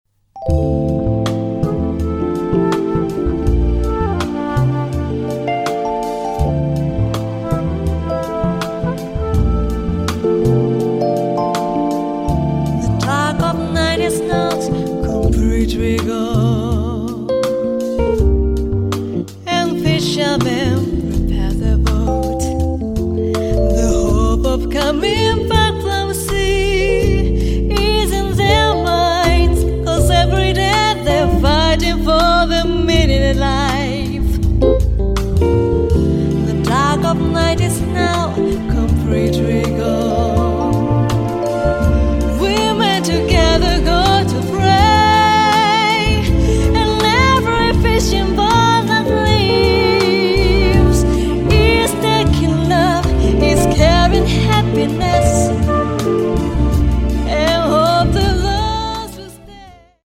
ジャコとフローラの切ないメロディデュエットにもひけをとらない出来栄えのテイク。